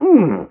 m_pain_2.ogg